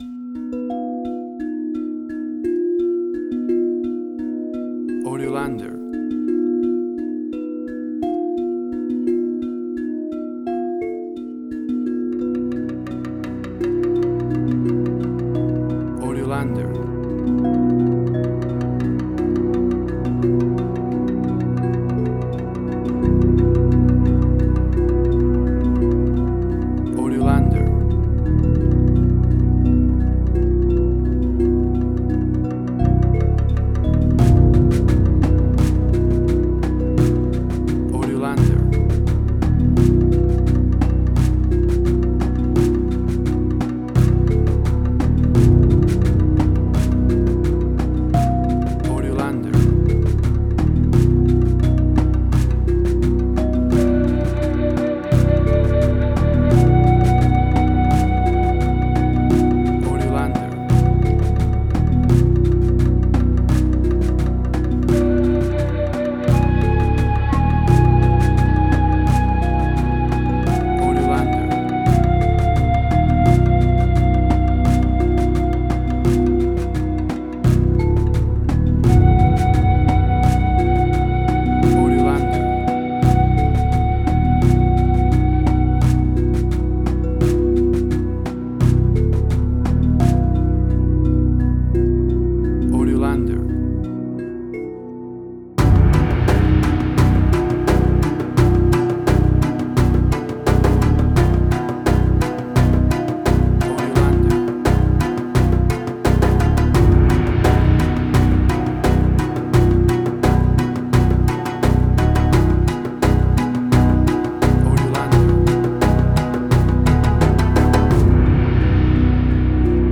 Asian Ambient.
WAV Sample Rate: 16-Bit stereo, 44.1 kHz
Tempo (BPM): 86